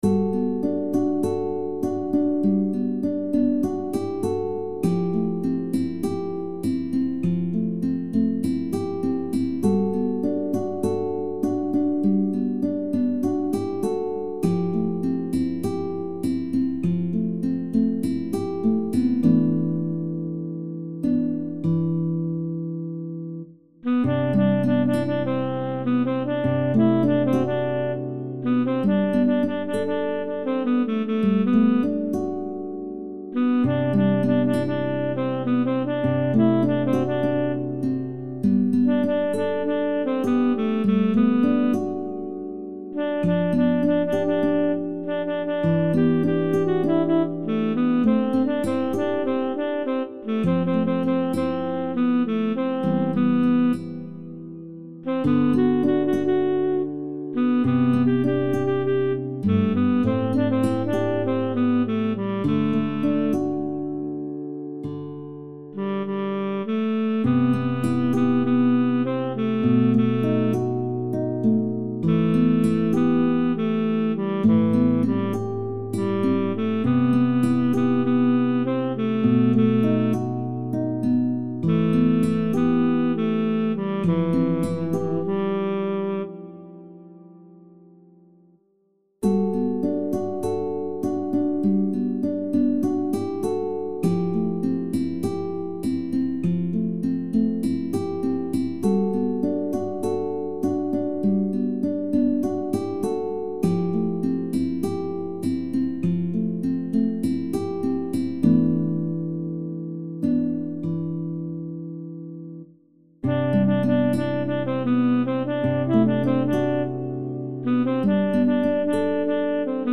SSAB met solo | SATB met solo